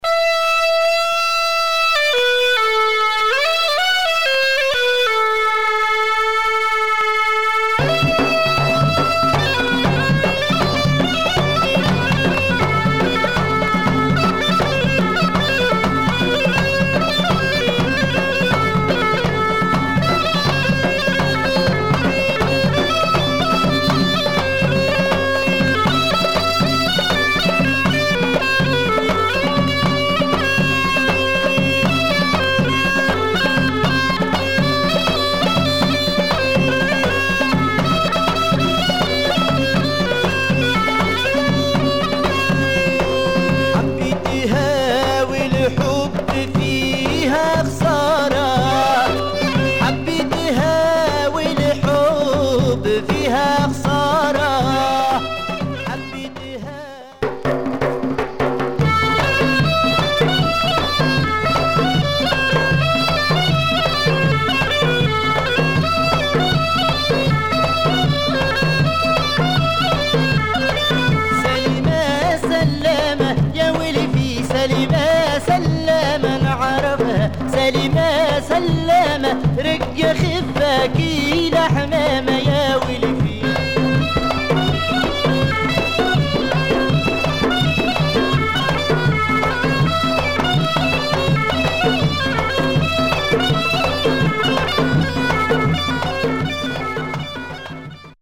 Raw and loud arabic trance music and chorus from Tunisia.